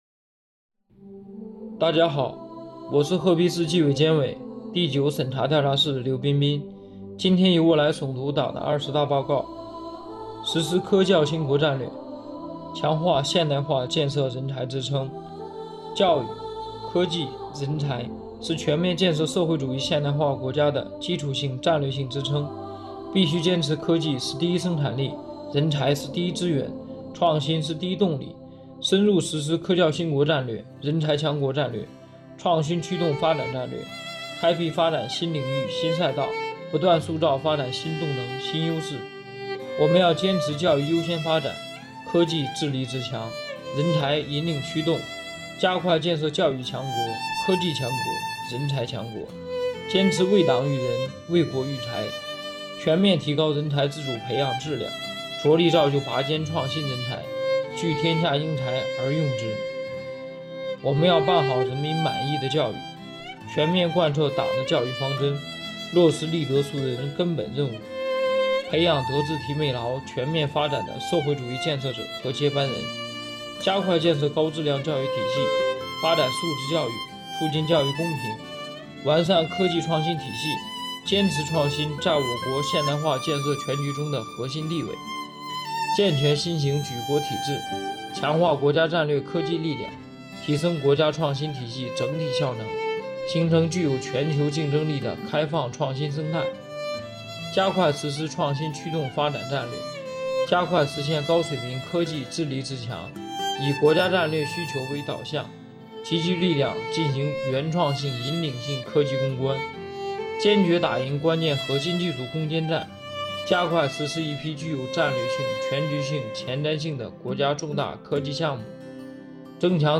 诵读内容